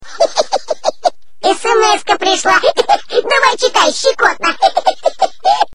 SMS-ка пришла! (смешки)